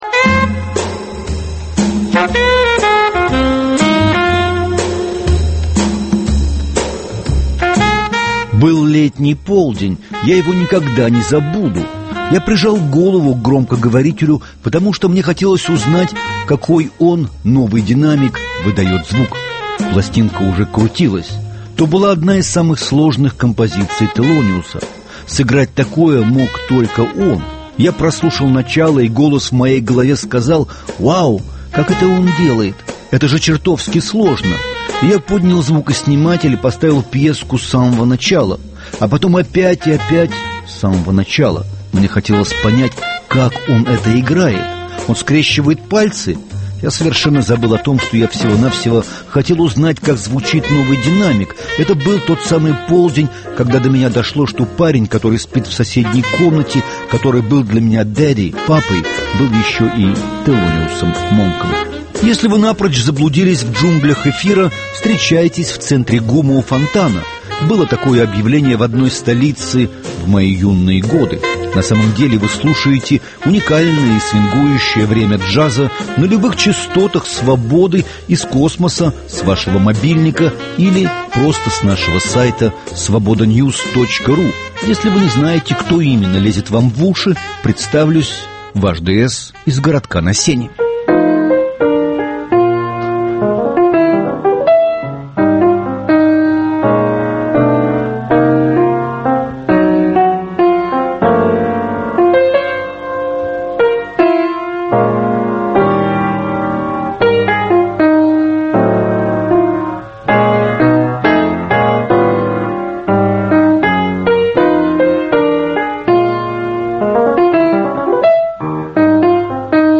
По случаю 25-летия Института Т.С. Монка его сына Т.С.-Третий выступает с комментарием об отце. В основном о своем детстве.